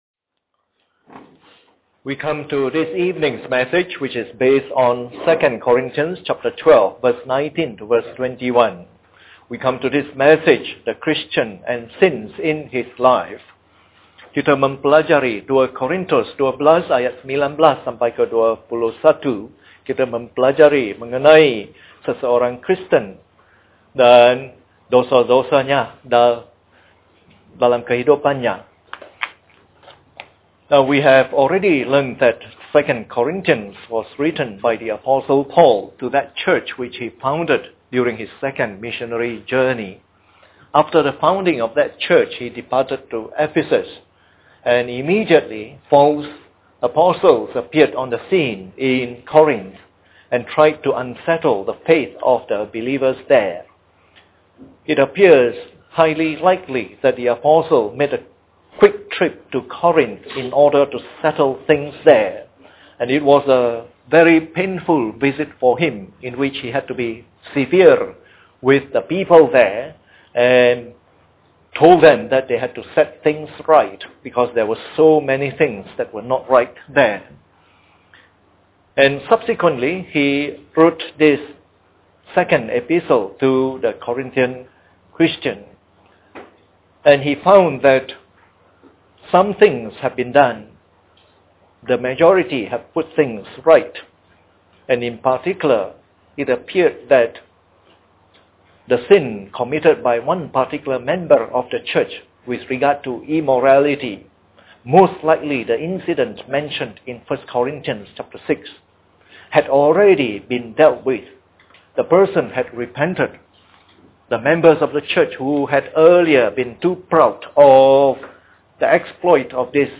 This is part of the “Selected 2 Corinthians” evangelistic series delivered in the Evening Service.